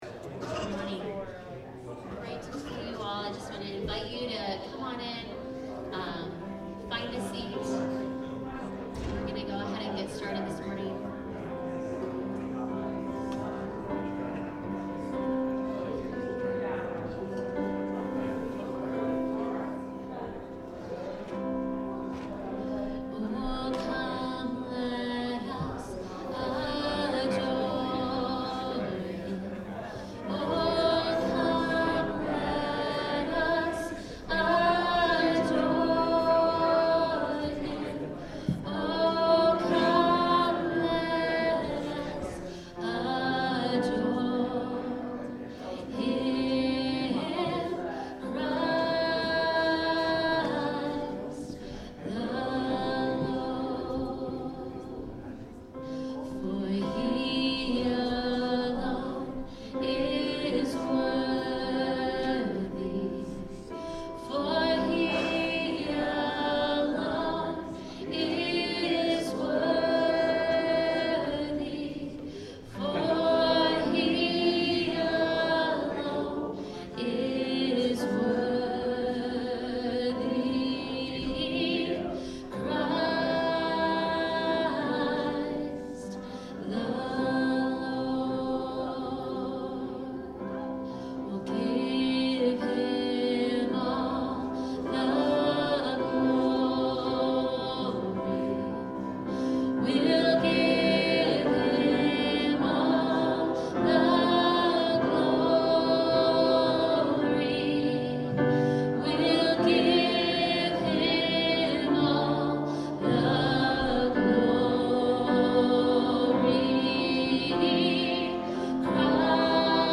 Sermons | Mission 72